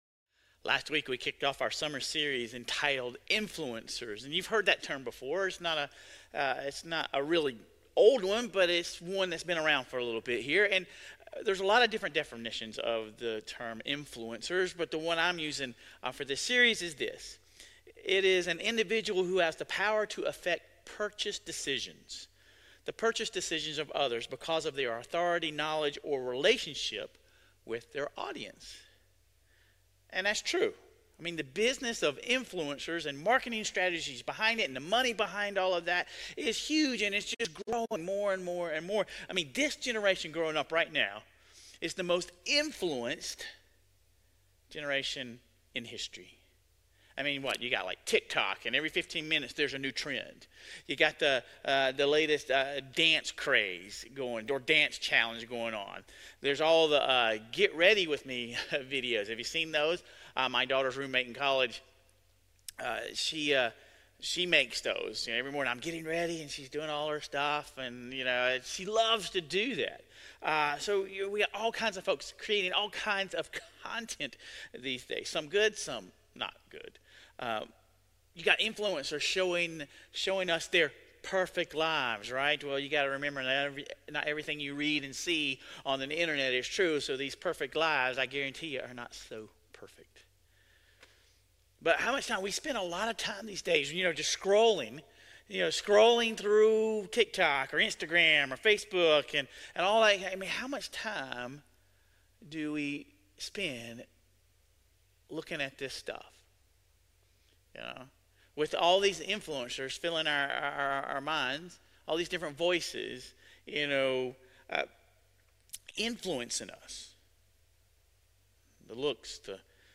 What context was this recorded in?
This week's message was delivered in our modern service, but was also pre-recorded, as we celebrated our high school graduates during Sunday's traditional service.